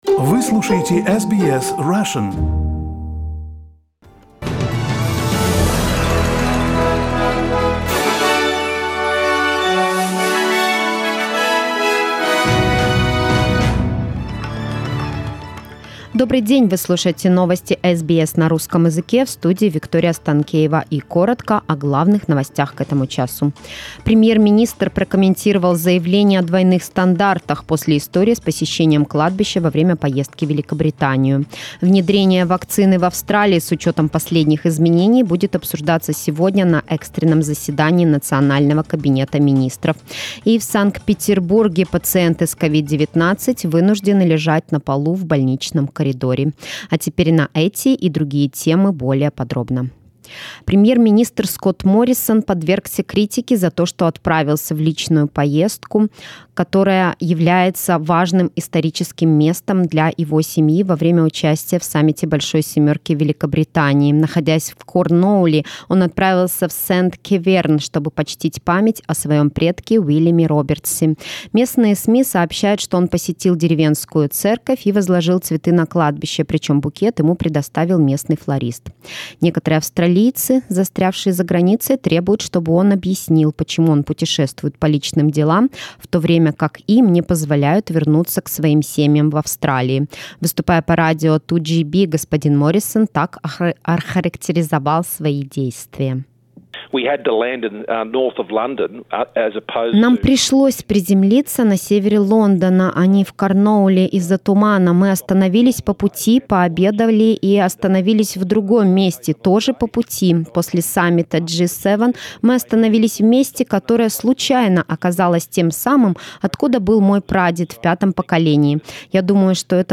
Главные новости Австралии и мира в понедельник: